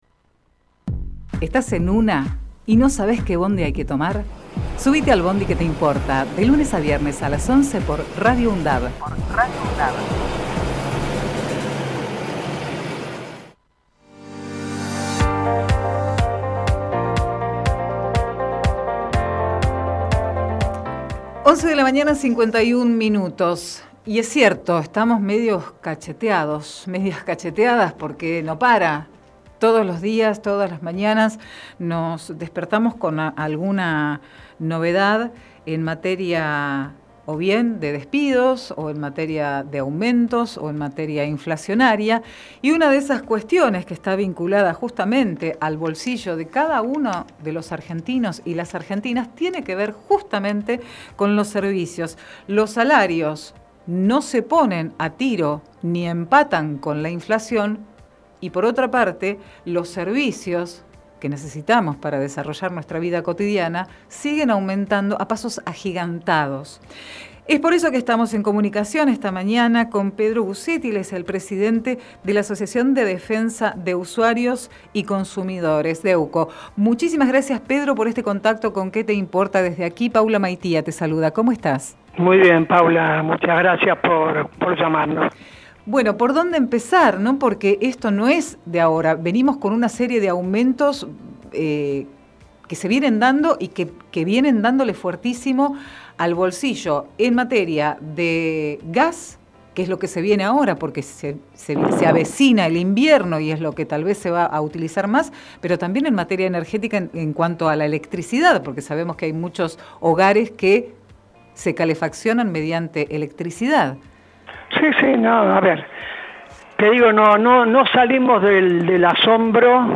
entervista